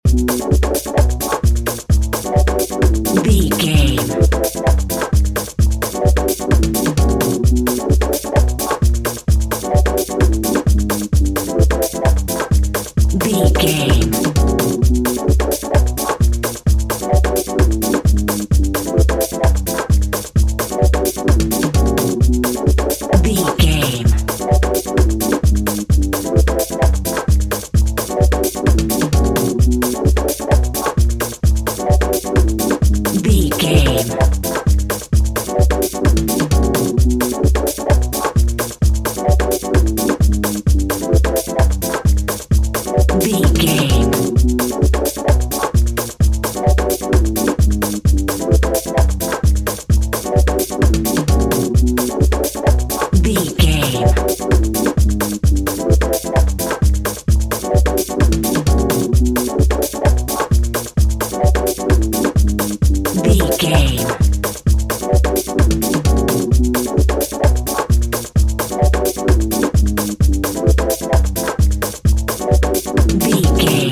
Aeolian/Minor
tropical
World Music